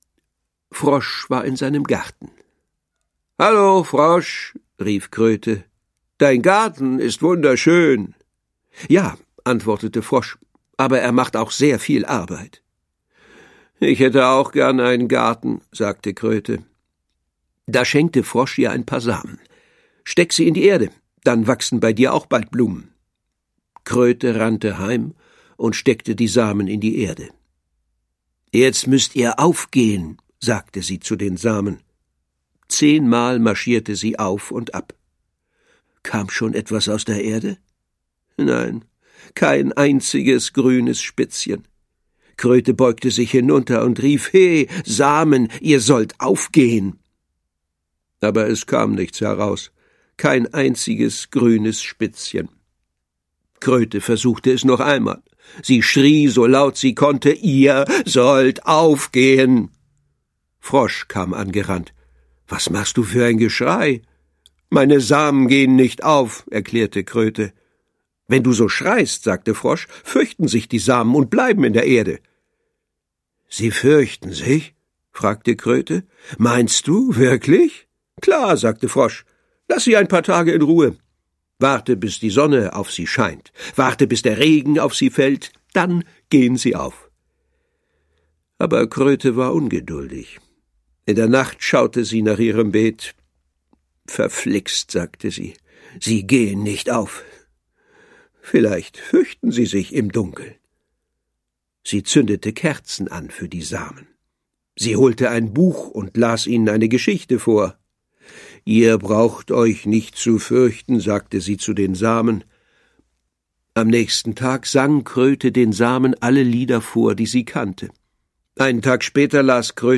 Schlagworte Freundschaft • Geschichten für Kleine • Hörbuch; Lesung für Kinder/Jugendliche • Tiere